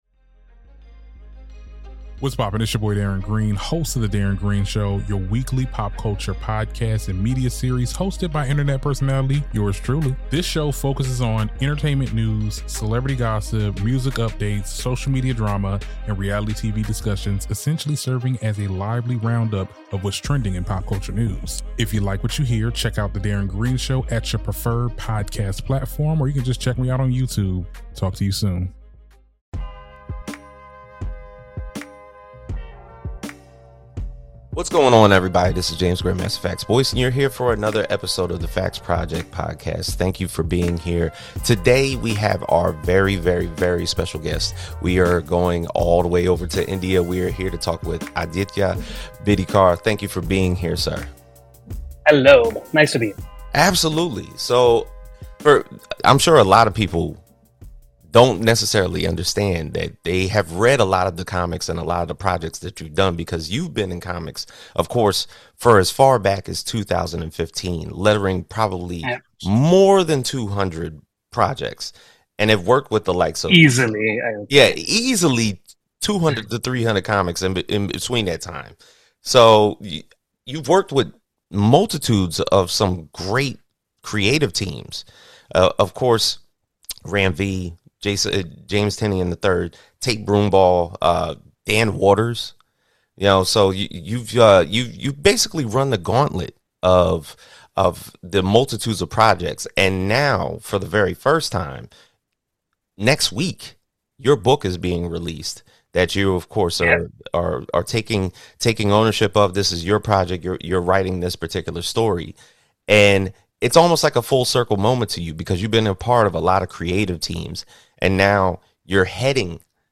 Journaling interviews about society in everyday Nerd and Geek culture through the lens of Comics, Music, Nightlife, and Film proving through study that everyone has a little nerd in them